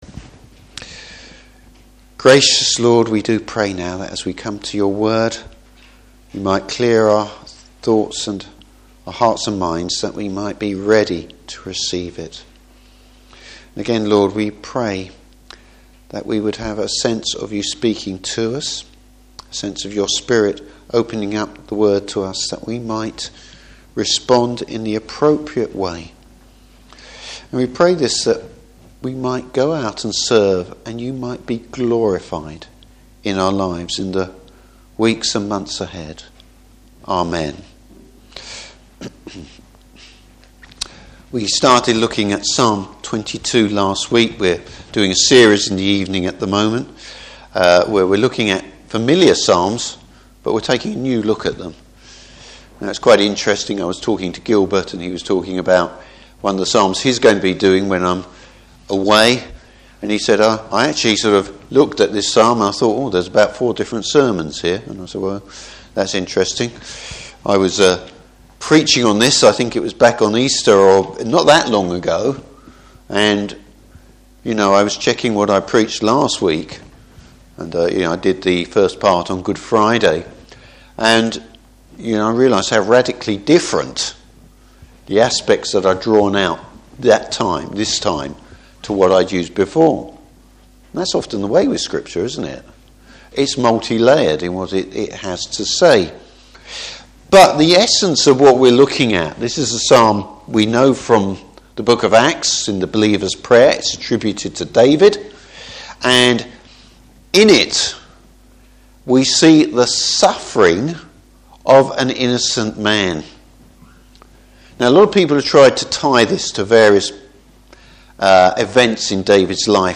Service Type: Evening Service The triumph of the resurrected Christ.